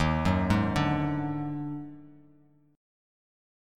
D#mM7bb5 chord